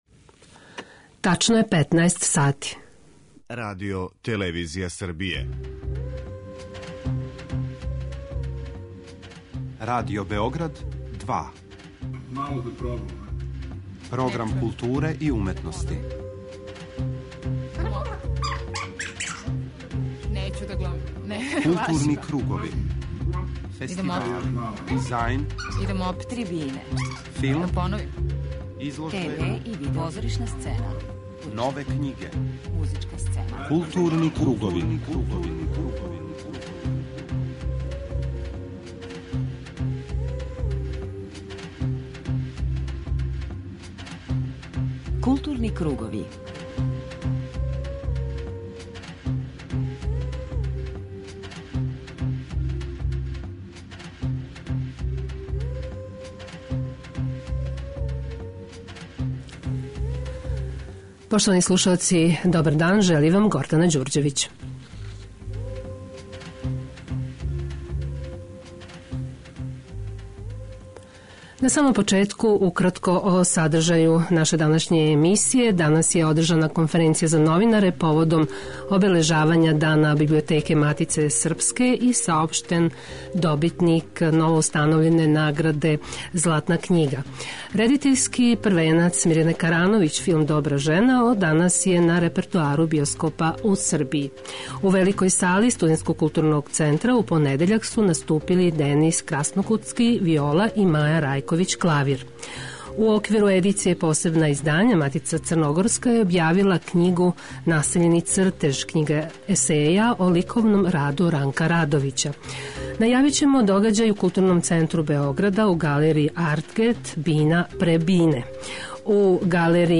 преузми : 40.81 MB Културни кругови Autor: Група аутора Централна културно-уметничка емисија Радио Београда 2.